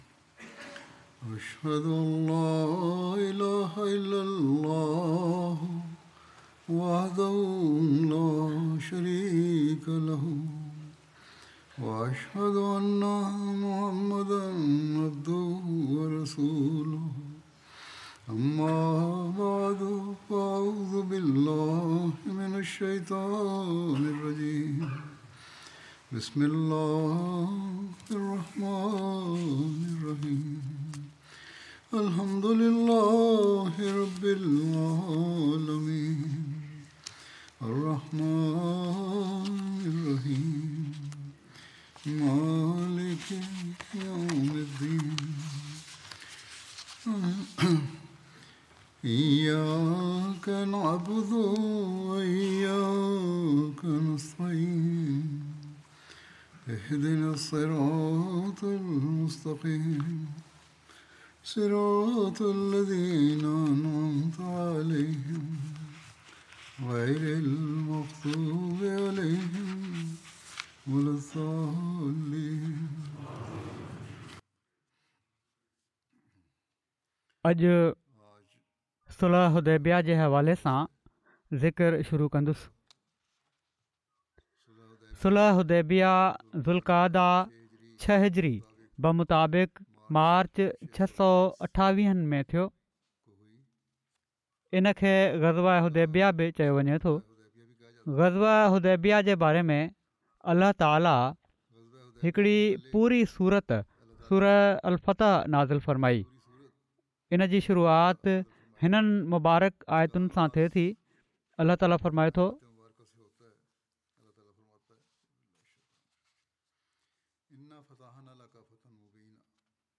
Sindhi Translation of Friday Sermon delivered by Khalifatul Masih